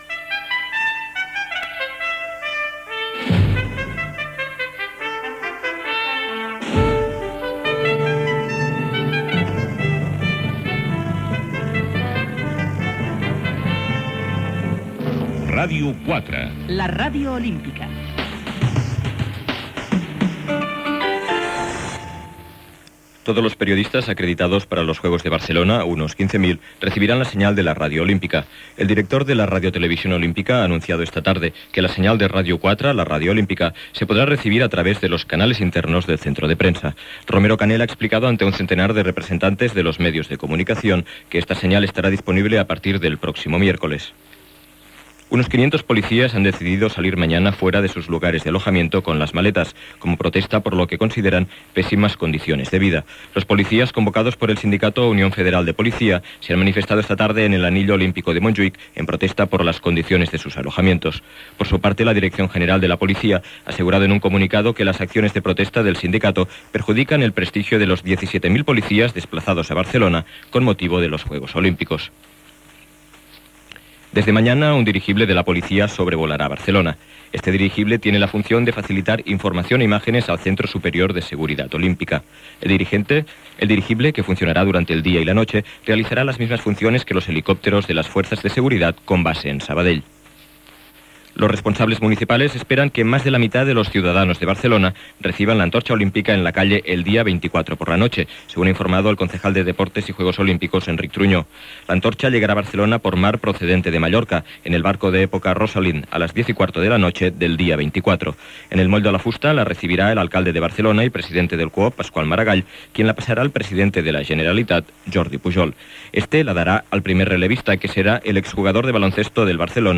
Sintonia, resum informatiu en castellà: la ràdio olímpica, protestes dels policies espanyols, dirigible, rebuda de la torxa olímpica, etc. Resums informatius en italià i japonès
Informatiu